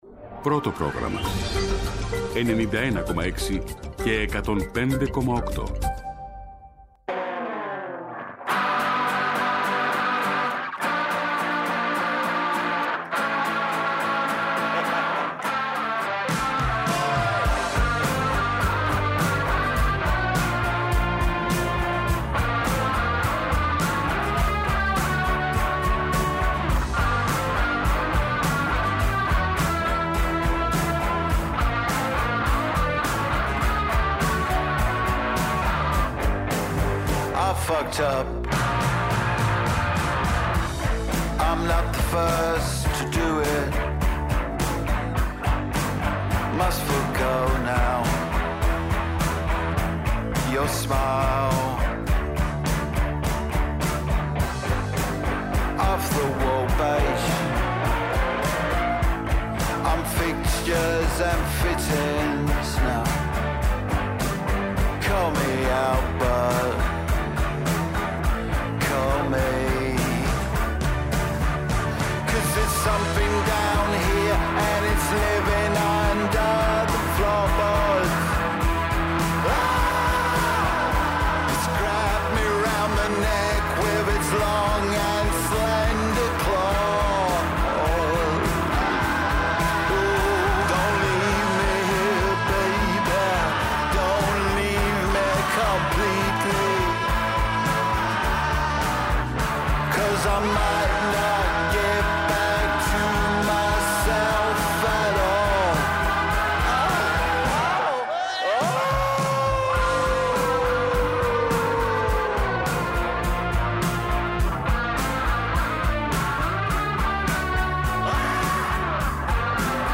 Ακούστε και αυτή την Παρασκευή, on demand στο ERTecho, τις επιλογές του Γιάννη Πετρίδη από τη μουσική επικαιρότητα.